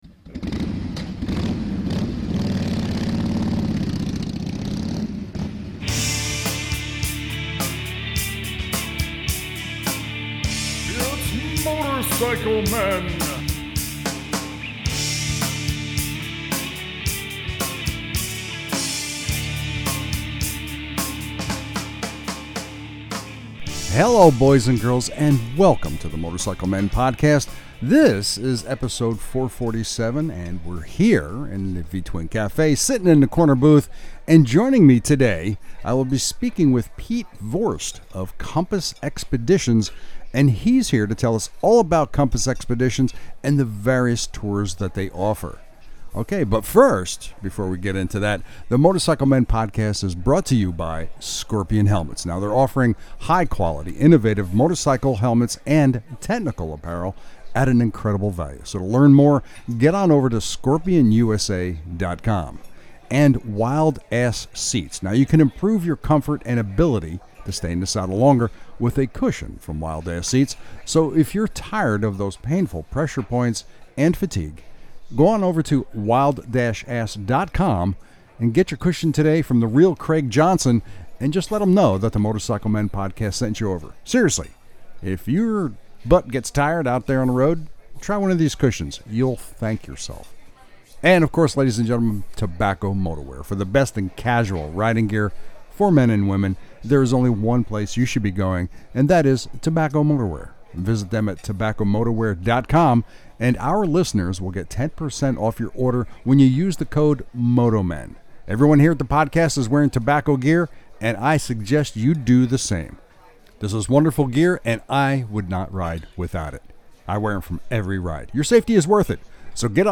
of Comapsss Expeditions and we sat down in the corner booth to talked about some of their tours and the amazing adventures that they offer.